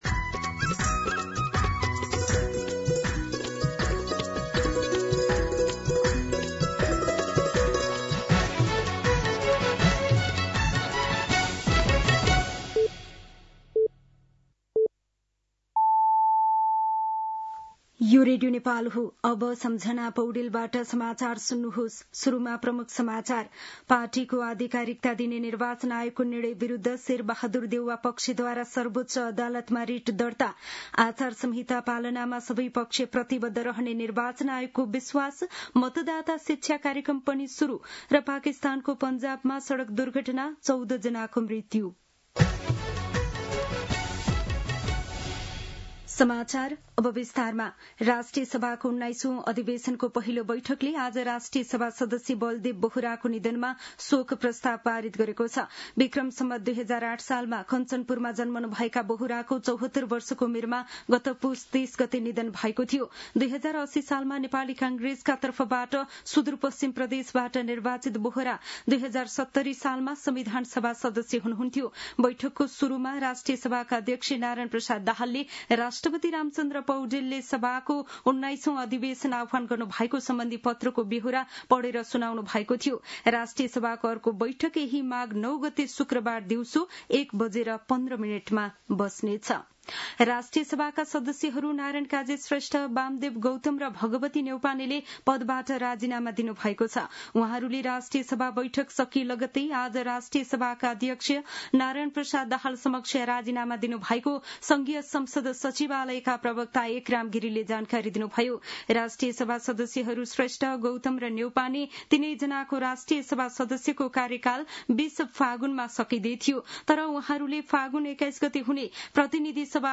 दिउँसो ३ बजेको नेपाली समाचार : ४ माघ , २०८२